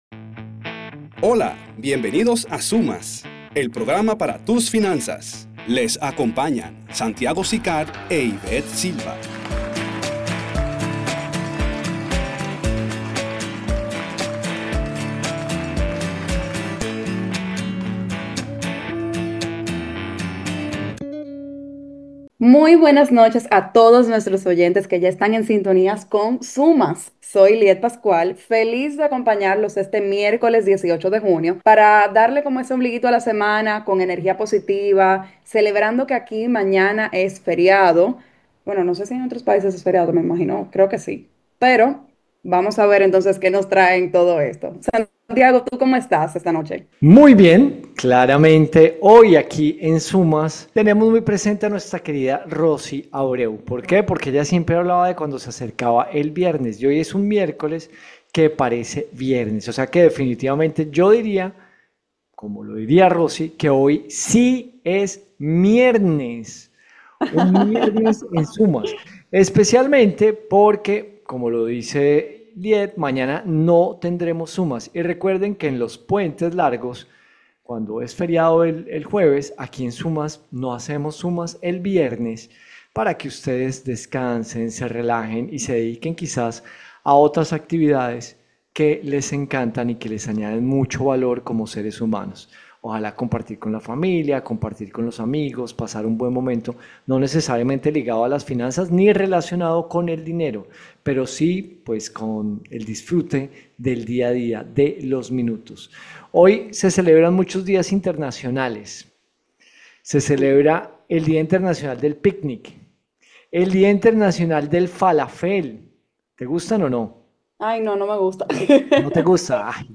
Escucha todo nuestro pograma de radio de hoy.